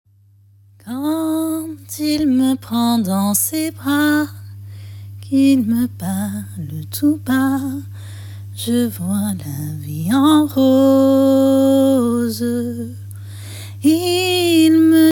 Extrait chant